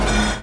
menuChange.mp3